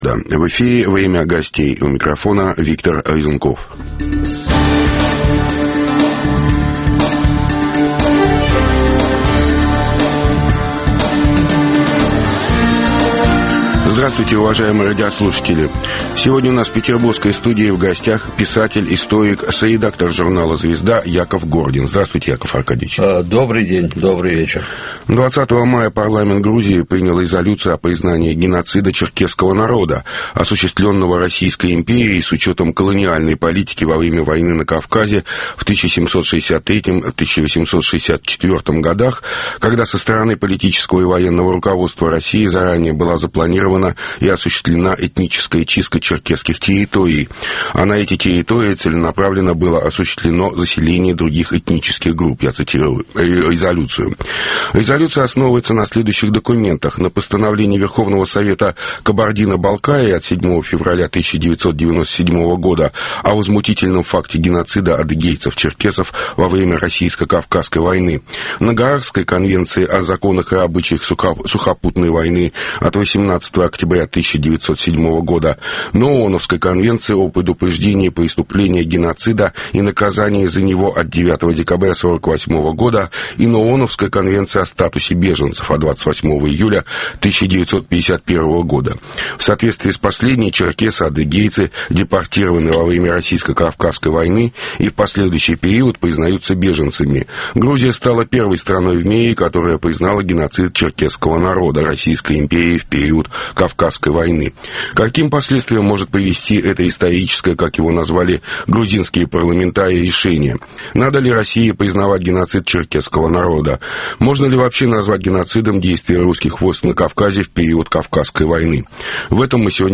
Должна ли Россия признать геноцид черкесов Российской империей во время войны на Кавказе? В петербургской студии в гостях - писатель, историк, соредактора журнала "Звезда" Яков Гордин.